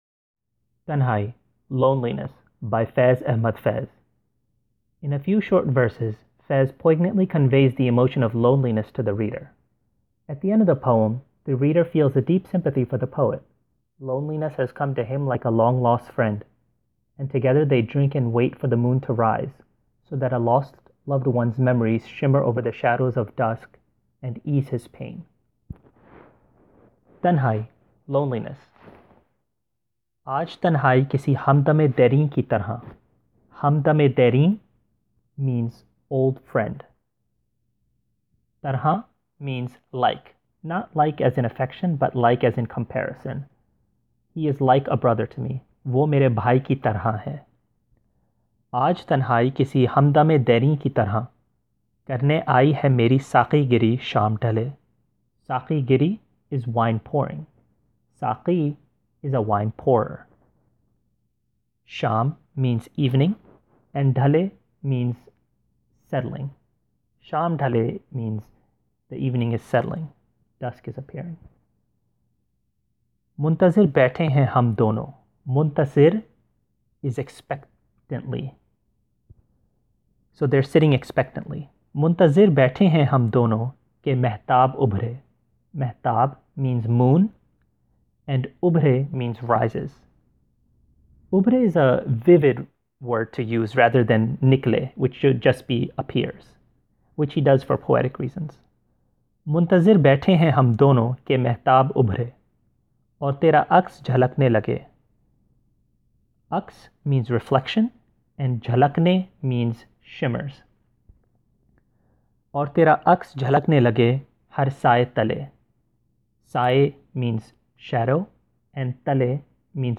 The first audio recording will translate the difficult words in English as I read along in Urdu.